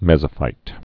(mĕzə-fīt, mĕs-)